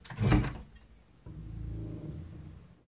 描述：摔倒后有人试图起床
标签： 里面记录 房屋
声道立体声